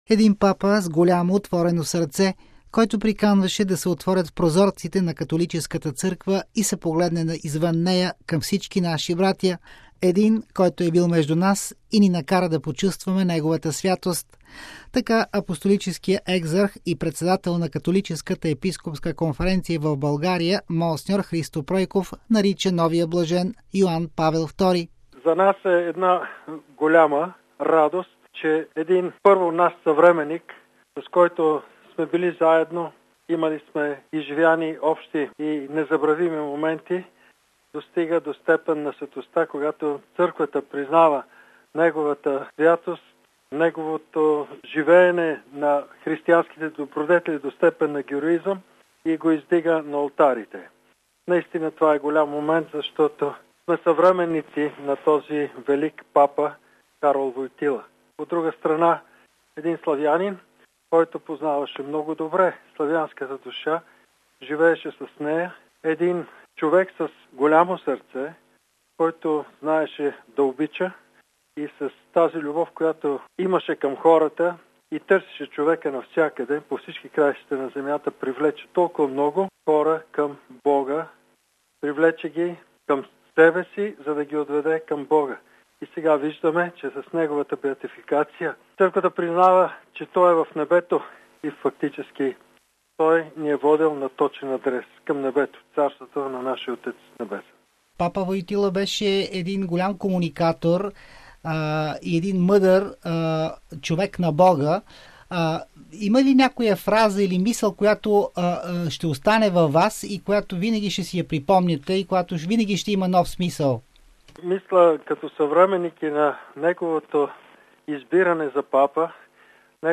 Така Апостолическият екзарх и председател на Католическата епископска конференция в България, монс. Христо Пройков, нарича новия Блажен Йоан Павел ІІ: RealAudio